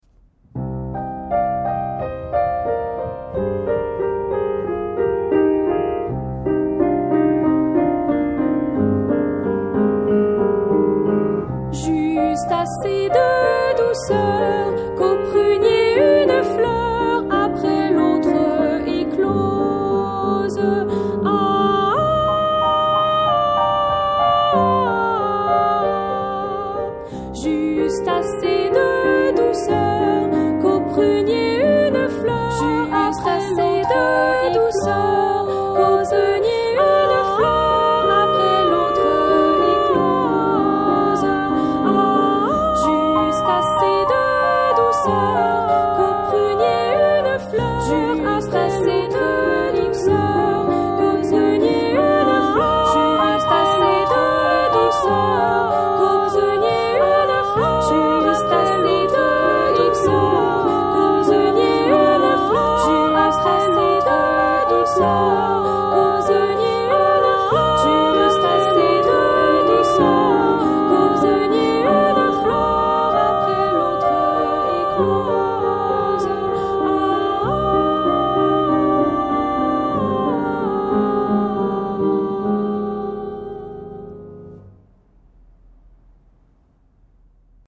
Audio canon du printemps à deux voix